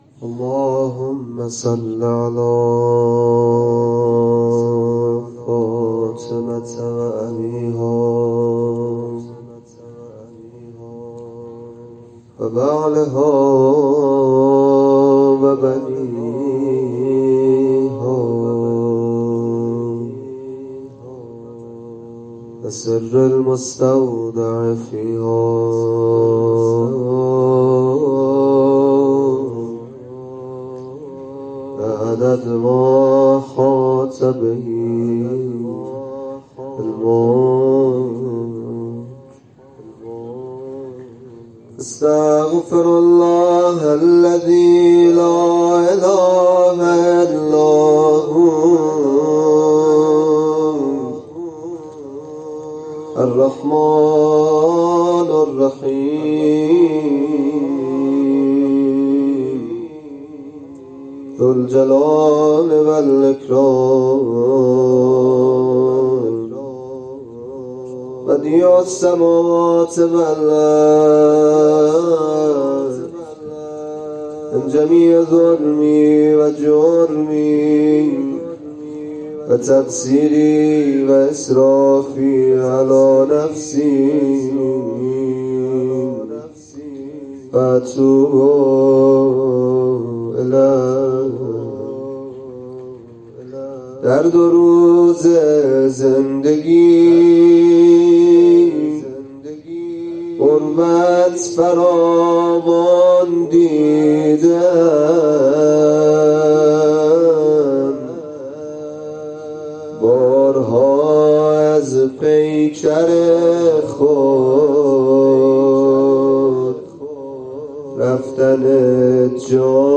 نوحه شهادت امام محمدباقر(ع)
روضه شهادت امام باقر